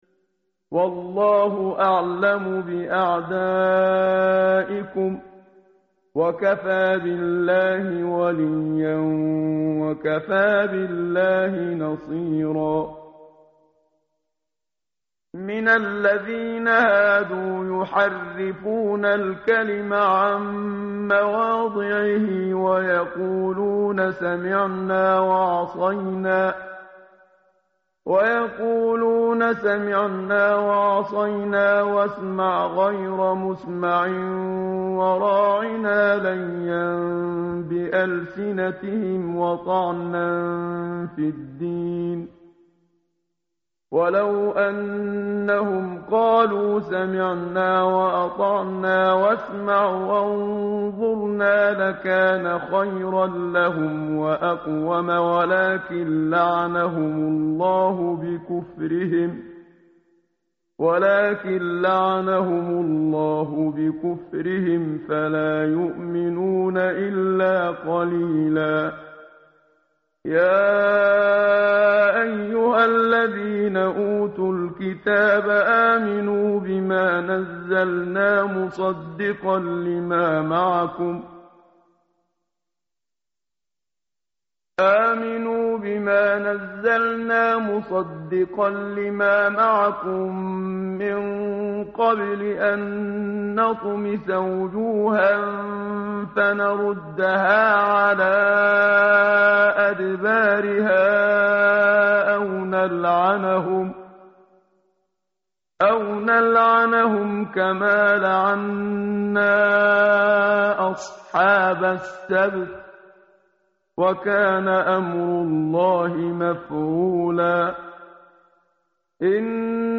متن قرآن همراه باتلاوت قرآن و ترجمه
tartil_menshavi_page_086.mp3